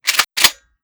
30-30 Lever Action Rifle - Lever 003.wav